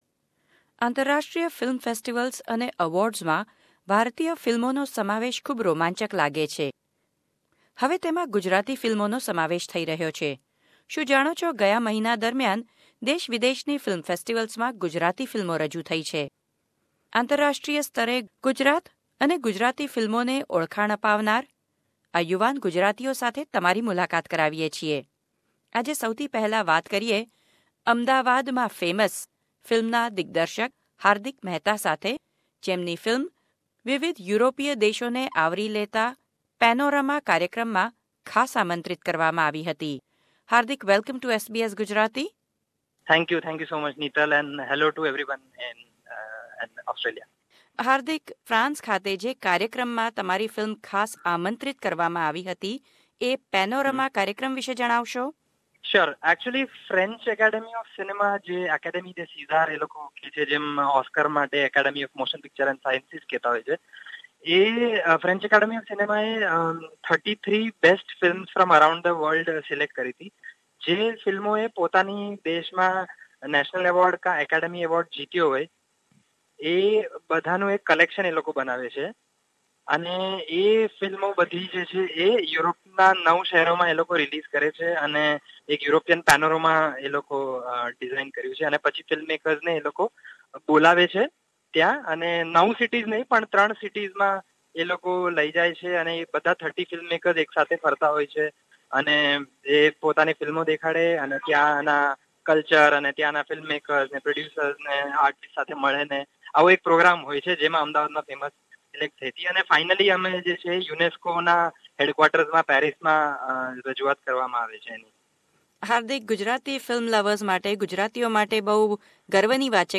વાત-ચીત